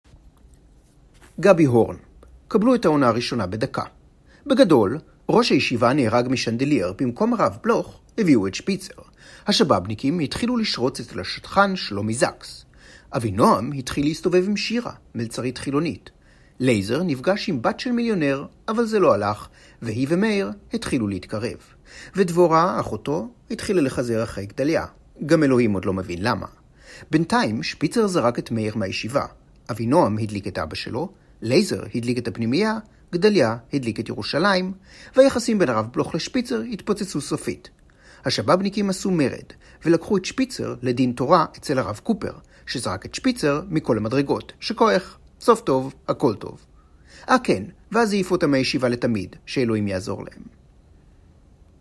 I am a professional actor, native Hebrew and English speaker (South African accent, very good with accents).
Sprechprobe: Werbung (Muttersprache):